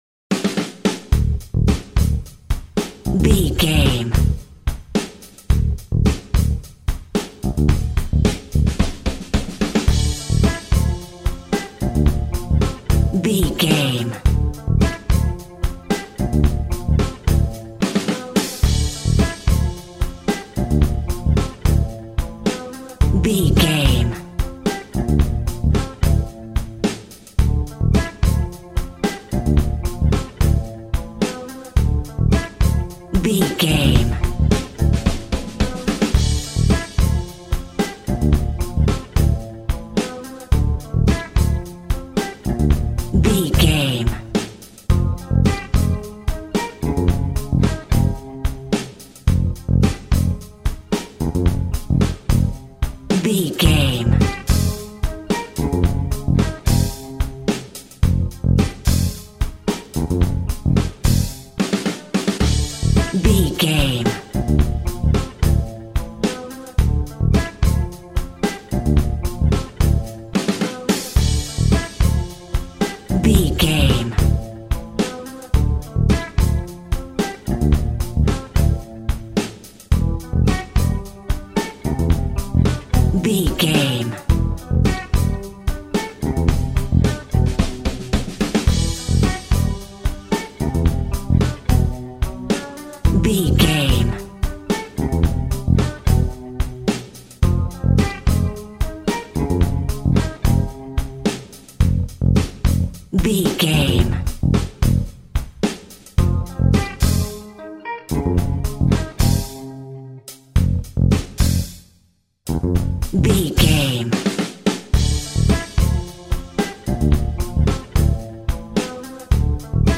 Eighties Smooth Rock.
Aeolian/Minor
groovy
driving
energetic
bass guitar
drums
electric guitar
Retro
synths